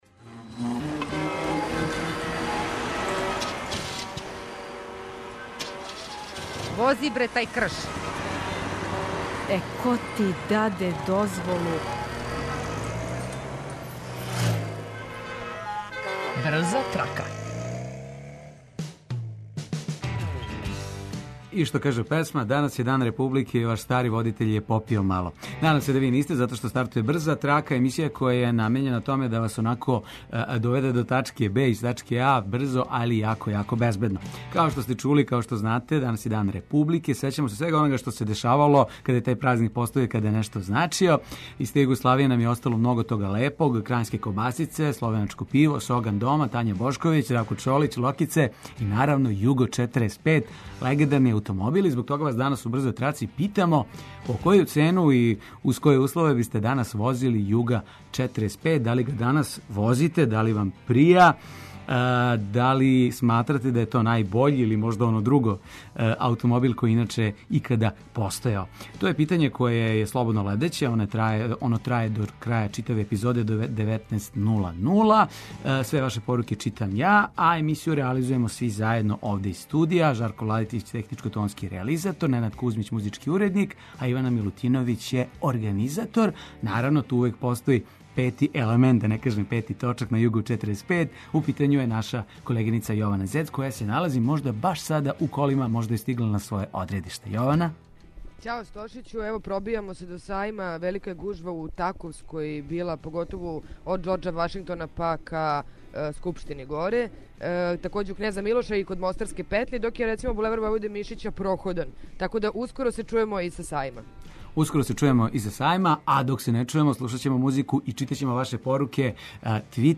Слушаоци репортери јављају новости из свог краја, па нам се јавите и ви.